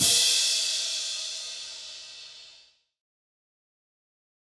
Crashes & Cymbals
BWB THE WAVE CRASH (18).wav